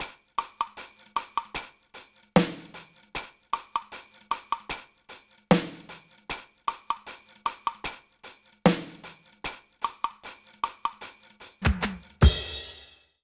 British REGGAE Loop 078BPM (NO KICK).wav